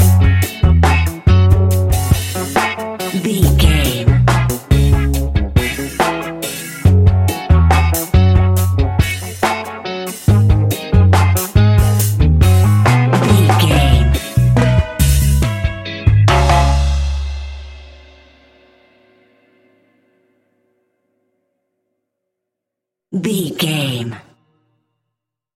Classic reggae music with that skank bounce reggae feeling.
Aeolian/Minor
C#
reggae instrumentals
laid back
chilled
off beat
drums
skank guitar
hammond organ
percussion
horns